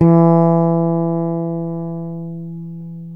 Index of /90_sSampleCDs/East Collexion - Bass S3000/Partition A/FRETLESS-E